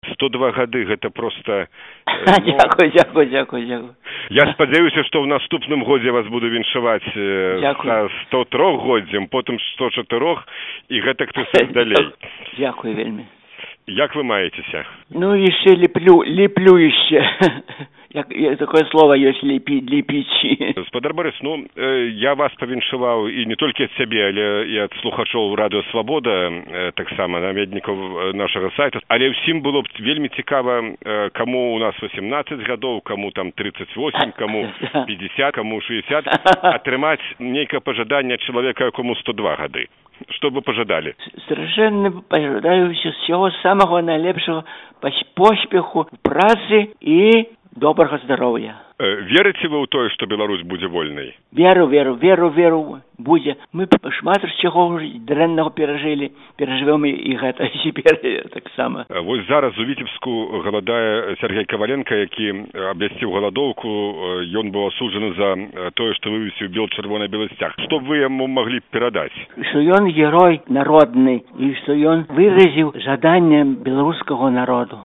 Гутарка з Барысам Кітом